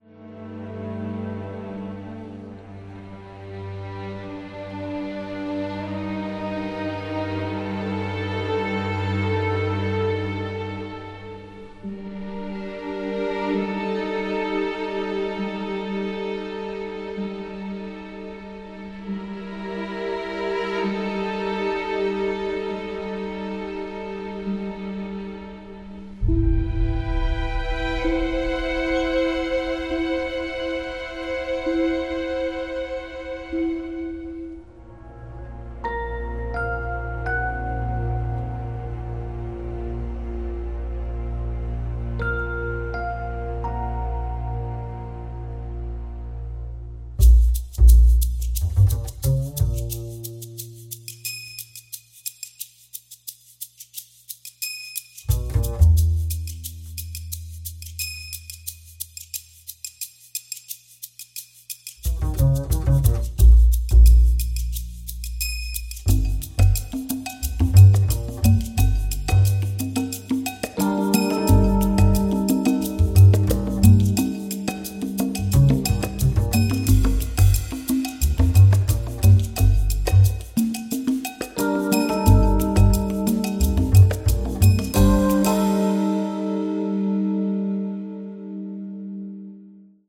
propulsive orchestral score
drenched in delightful old school film-noir vibes